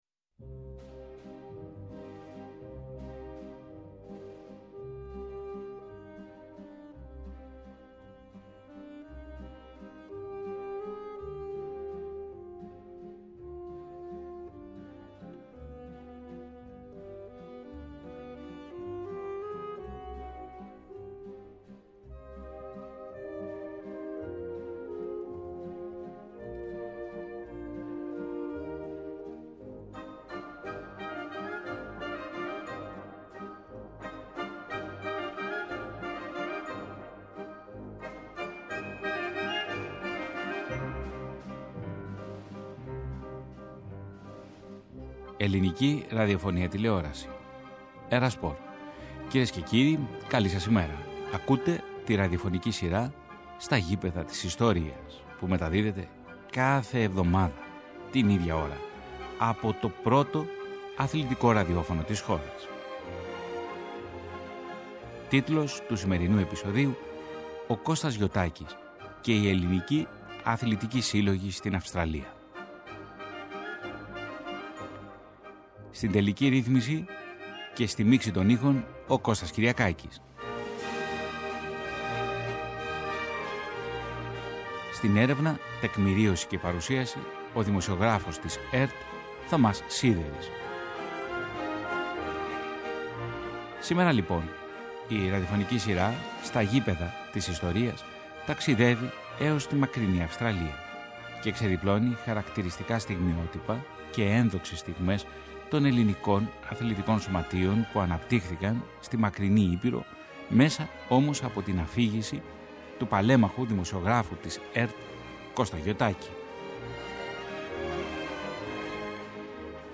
ΝΤΟΚΙΜΑΝΤΕΡ